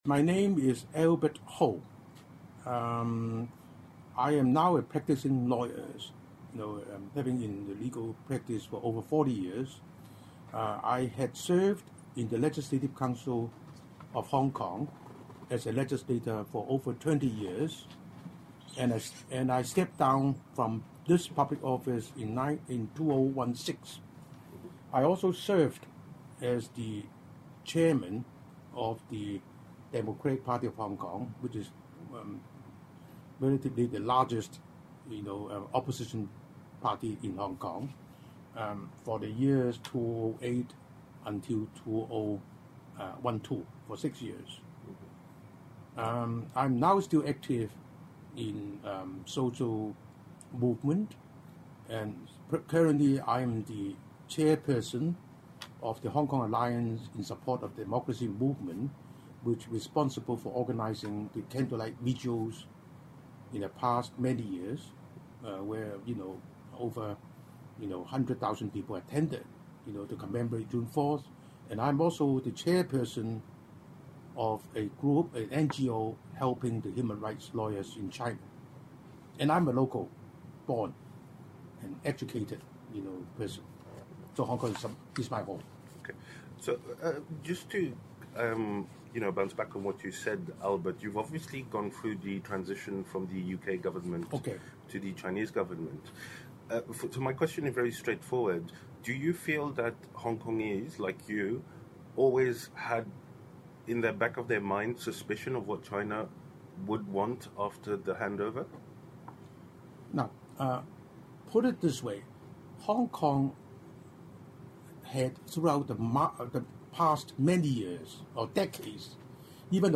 Hong Kong protests interview : Albert Ho
Albert Ho Chun-yan, chairman of the Hong Kong Alliance in Support of Patriotic Democratic Movements in China.